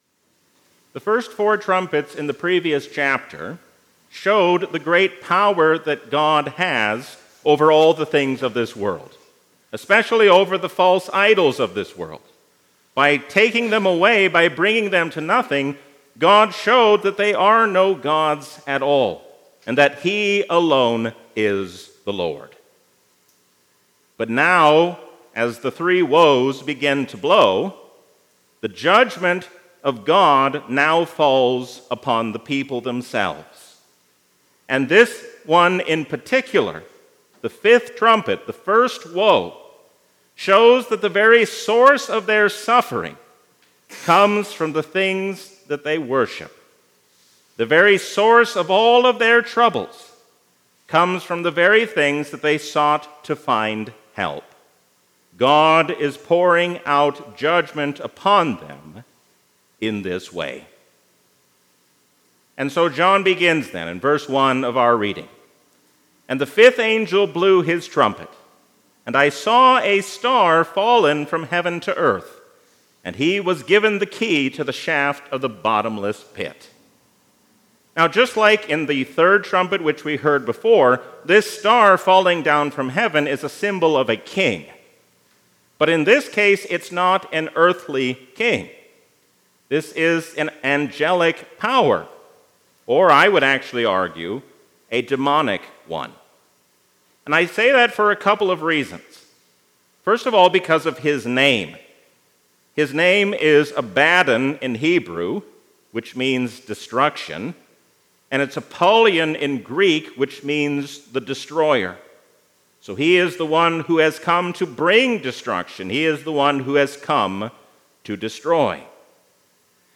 A sermon from the season "Trinity 2024." Look to Jesus who sets you on the way of life, and you will find blessing both now and in eternity.